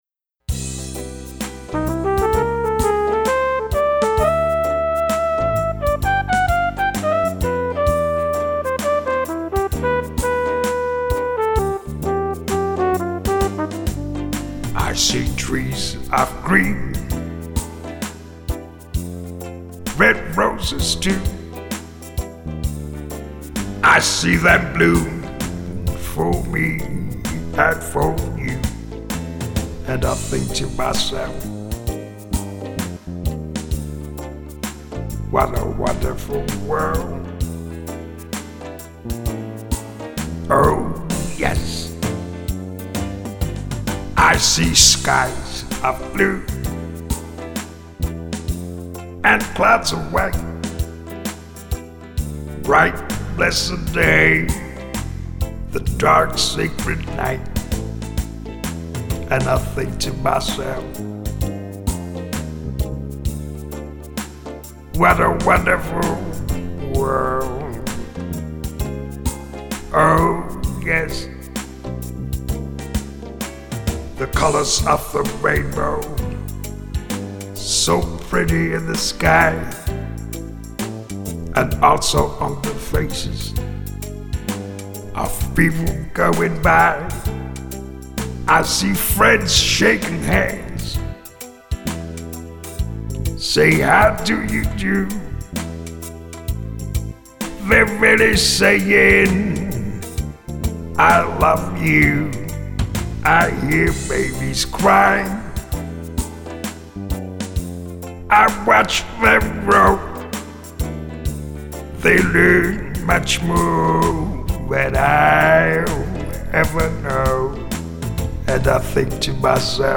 Cool Jazz Ballads & Waltzes / Smooth Jazz / Show Tunes
My Arrangement